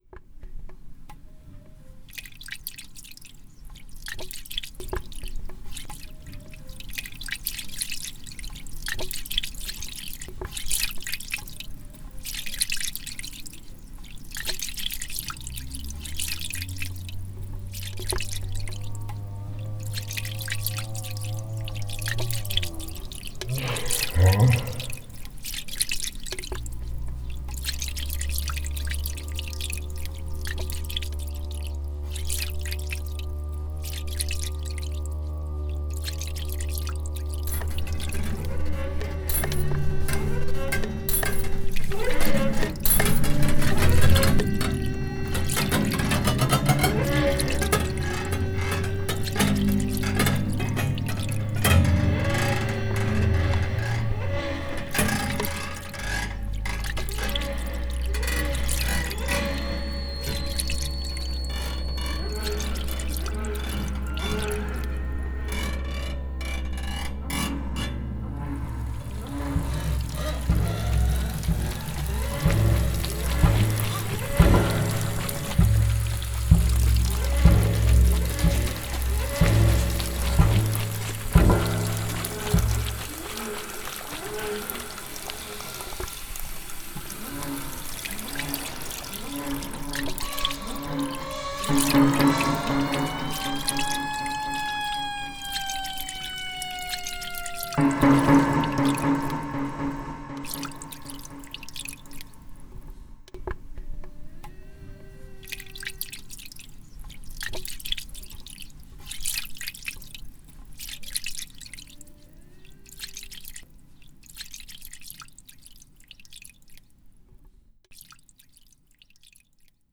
Les élèves musiciens ont proposé deux créations pour accompagner un "procès fictif écologique", simulation de procès autour d’une affaire de pollution majeure du Rhin. Explorant le genre du soundscape (paysage sonore), ils ont apporté un éclairage sonore à la question du "fleuve en souffrance".
Ils ont d'abord dévoilé une pièce entièrement électroacoustique